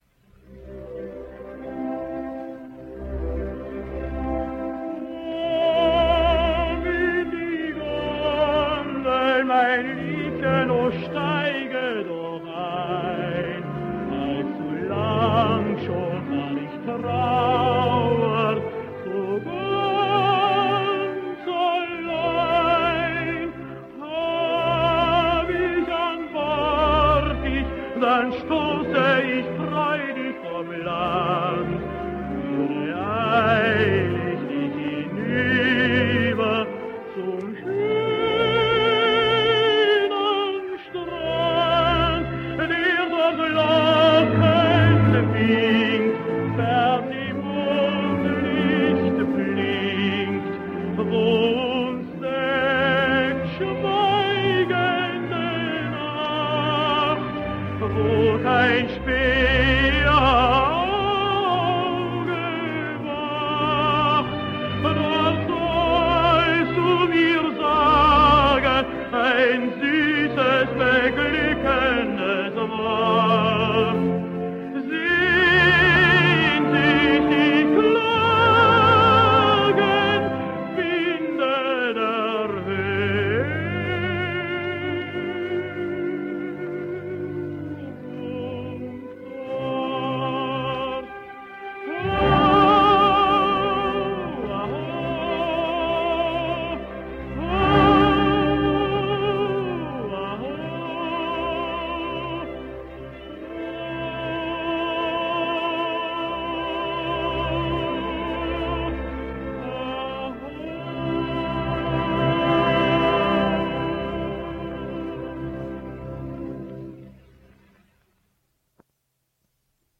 Austrian tenor.
Both Patzak and Erb were noted for the silvery timbre of their voices.
But for his first record, a sample of his operetta work.